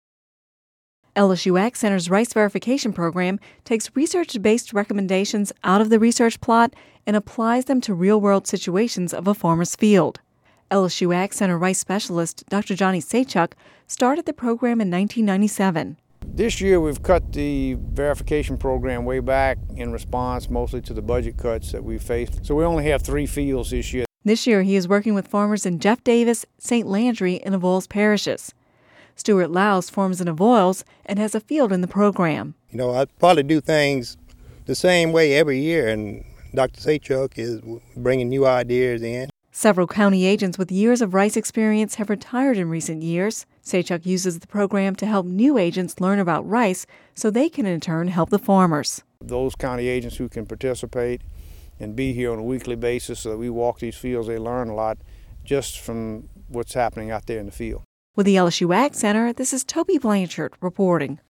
(Radio News 05/03/10) The LSU AgCenter’s rice verification program takes research-based recommendations out of the research plots and applies them to the real-world situations of farmers' fields.